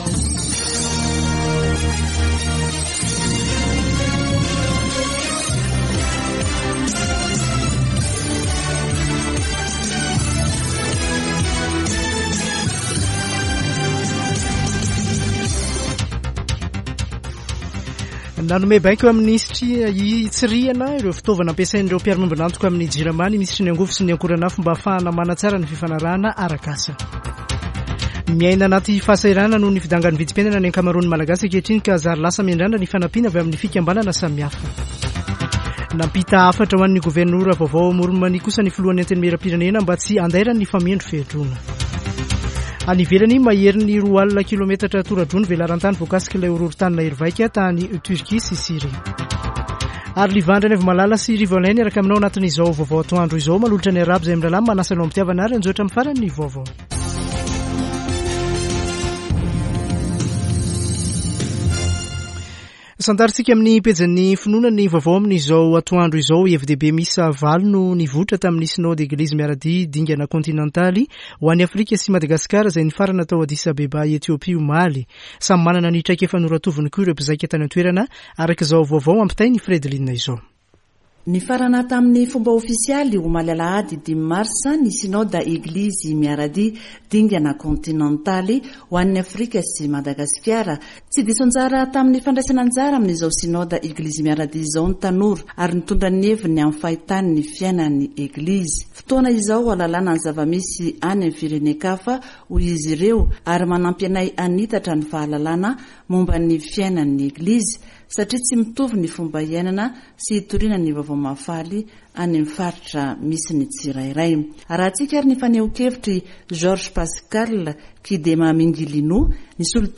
[Vaovao antoandro] Alatsinainy 06 marsa 2023